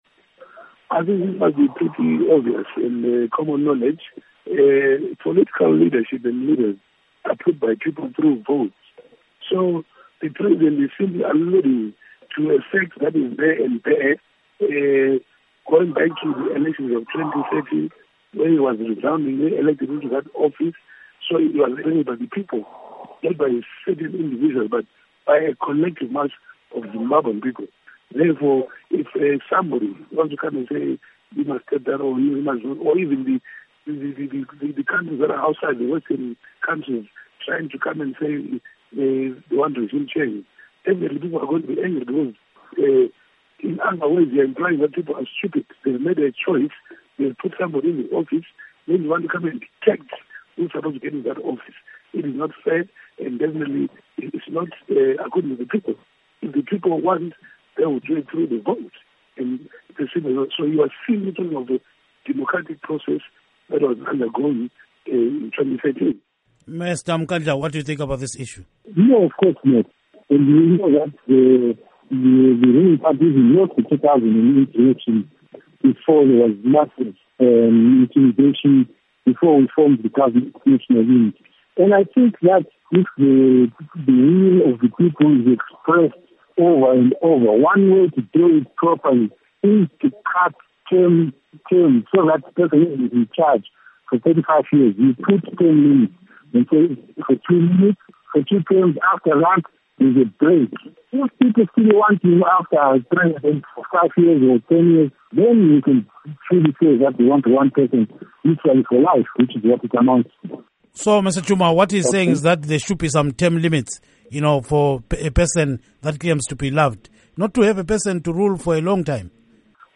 For perspective Studio 7 reached Zanu PF lawmaker, Joseph Tshuma, and Zapu member.
Interview